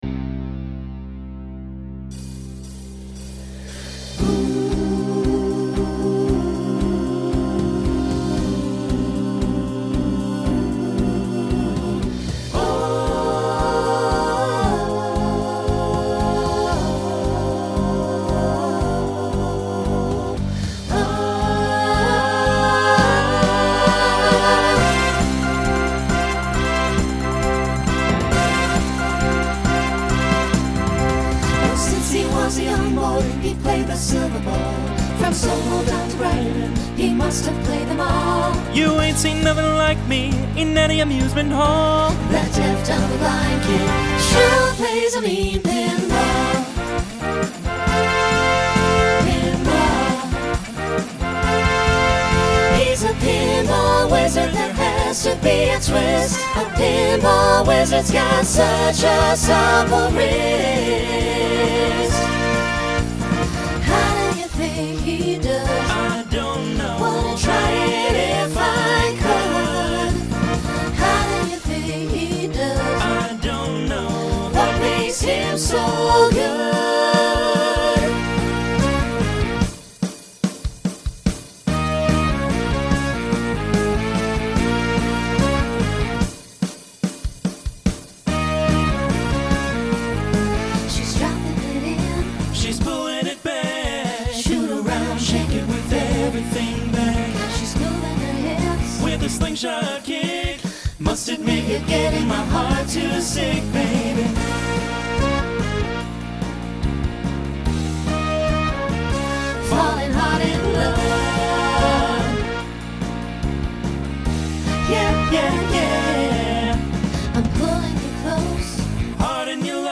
Genre Broadway/Film , Rock Instrumental combo
Voicing SATB